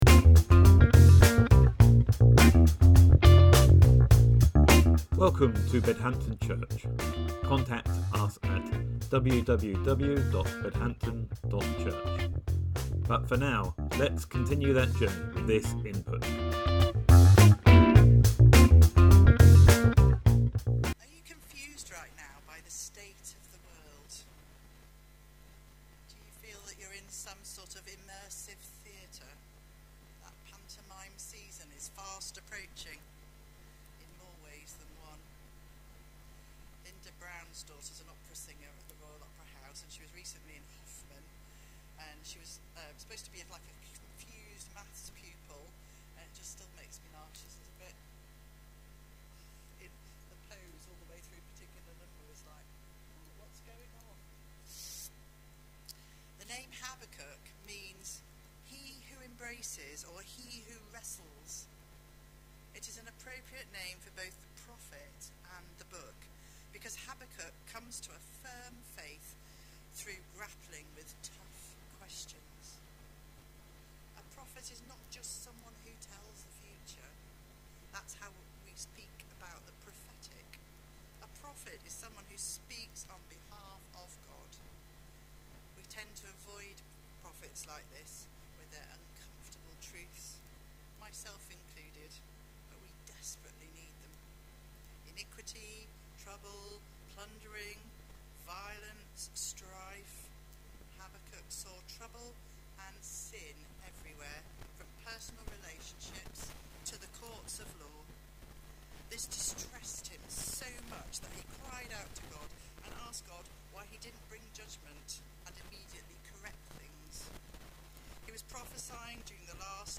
Sermon: Habakkuk 1.1-4,2:1-4